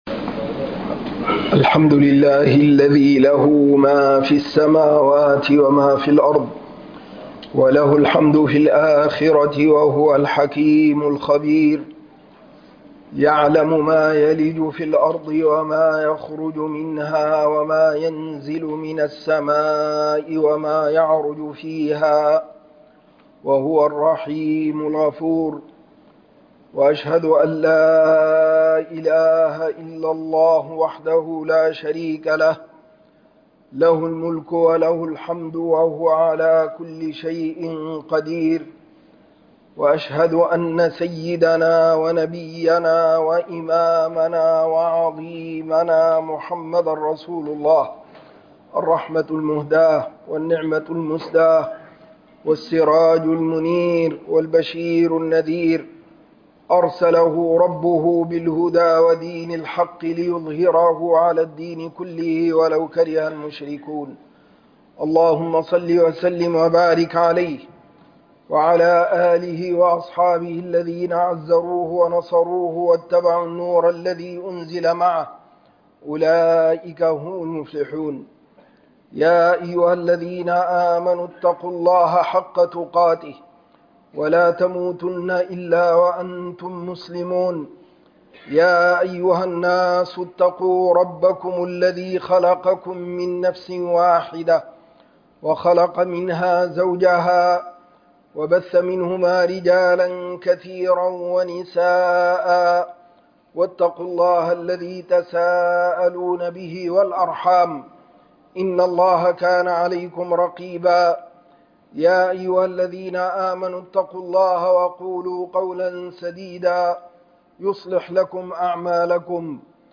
فتنة المال خطبة الجمعة 16